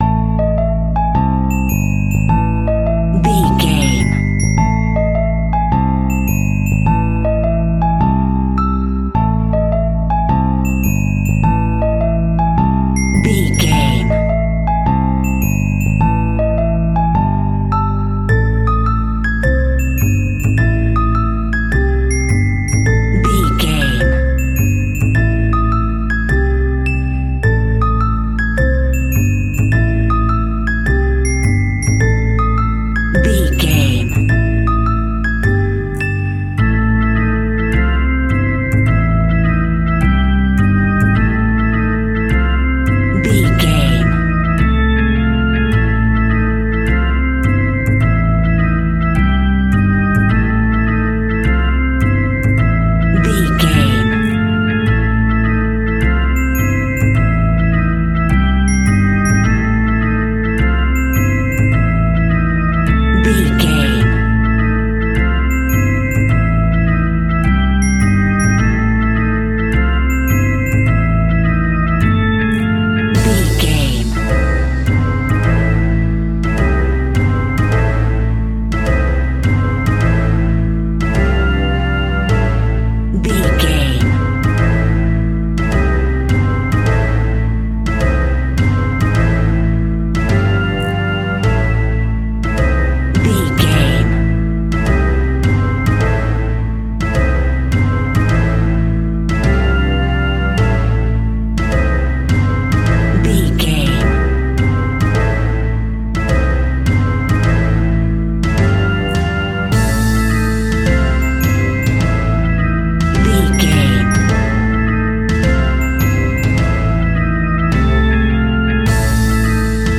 Aeolian/Minor
ominous
dark
suspense
haunting
eerie
piano
bass guitar
electric guitar
percussion
double bass
organ
drums